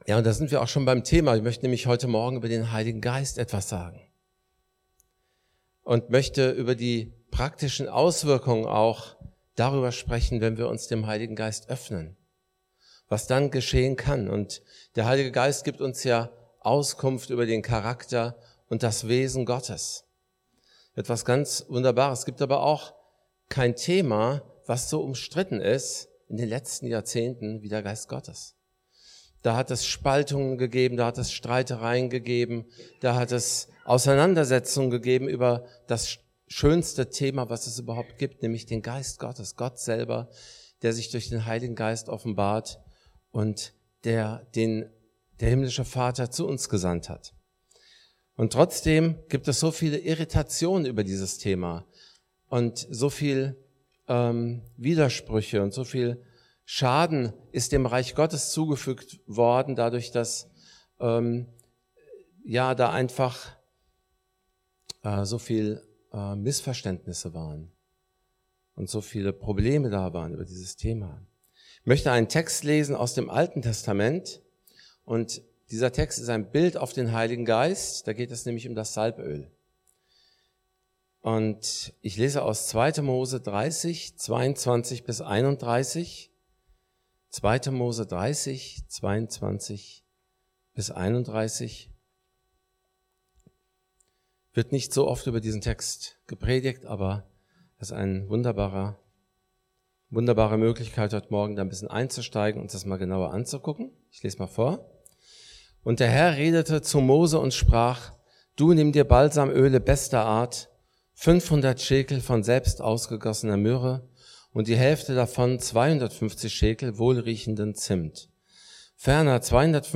Mose 30, 22-31 Dienstart: Predigt Bible Text: 2.